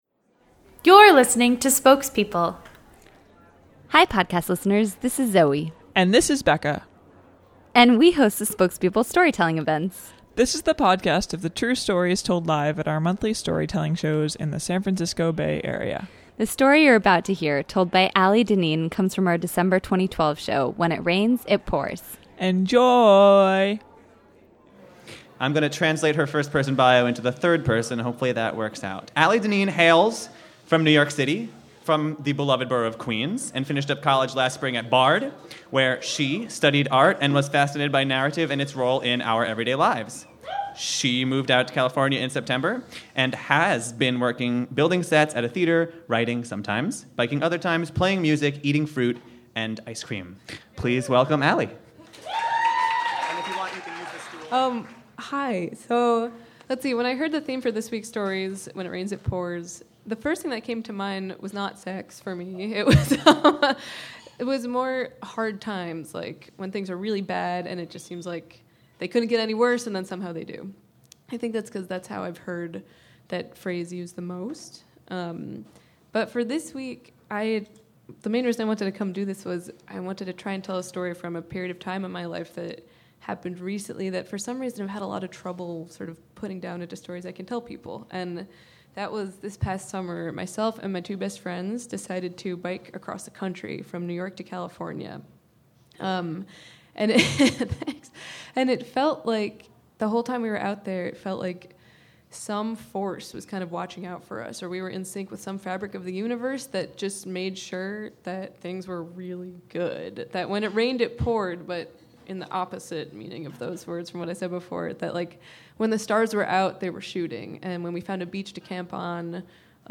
Today's storyteller relives the day of hail storms, lava caves, and free dairy queen, also known as just another Tuesday on this summer's cross-country bike trip. The mysteries of Idaho -- plus a bonus musical feature set to Ukulele! -- captivate the audience from our December 2012 show, When It Rains, It Pours.